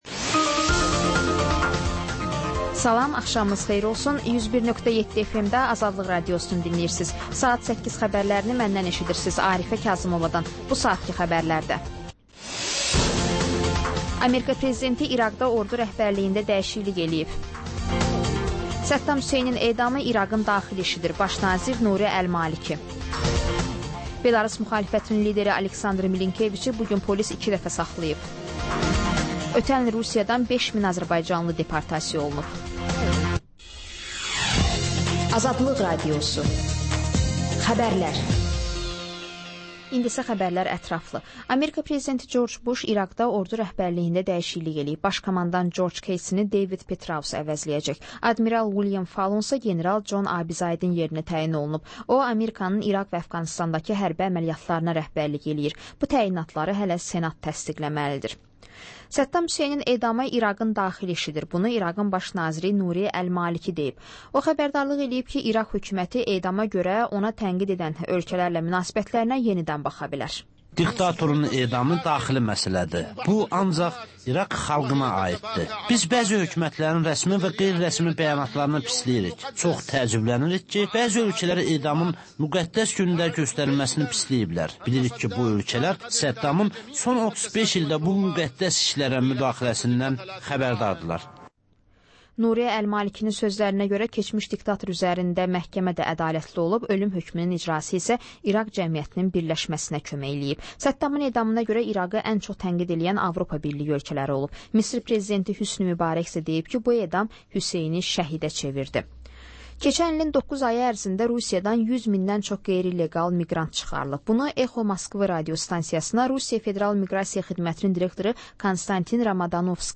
Xəbərlər, reportajlar, müsahibələr. Panorama: Jurnalistlərlə həftənin xəbər adamı hadisələri müzakirə edir. Və: Qafqaz Qovşağı: Azərbaycan, Gürcüstan və Ermənistandan reportajlar.